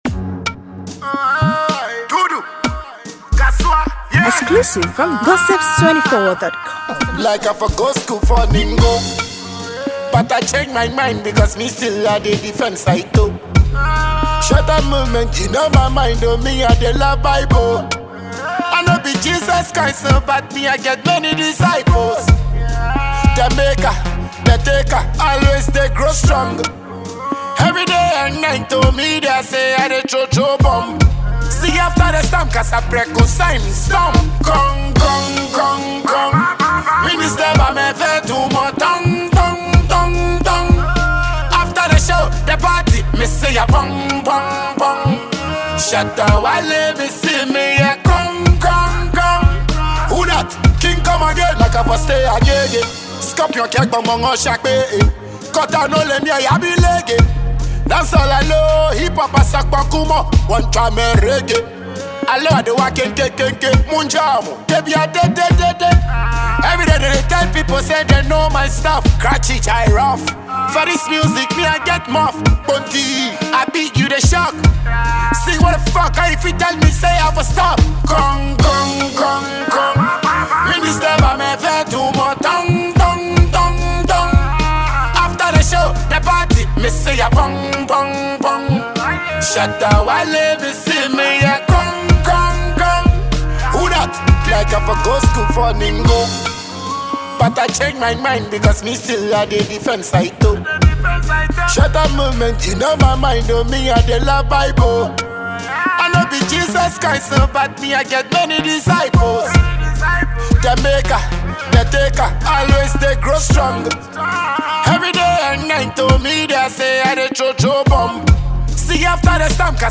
Multiple Award Winning Dancehall star